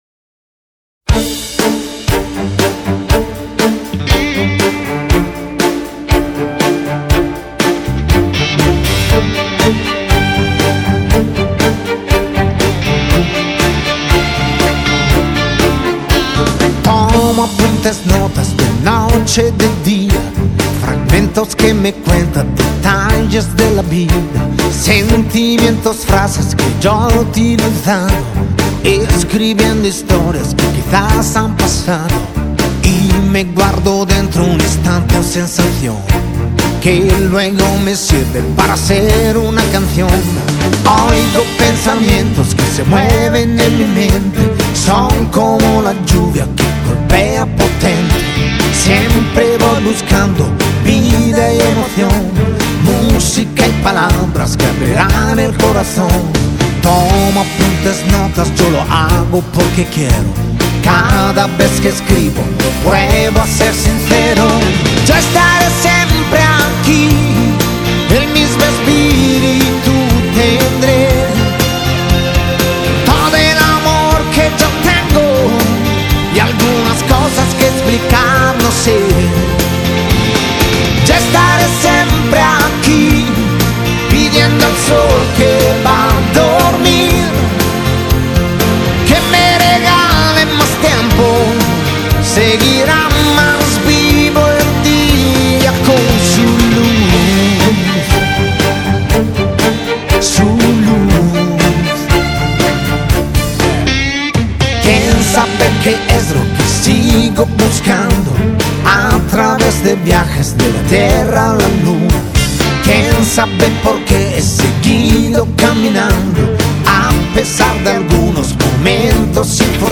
Latin Pop, Pop Rock